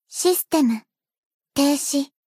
贡献 ） 分类:彩奈 分类:蔚蓝档案语音 协议:Copyright 您不可以覆盖此文件。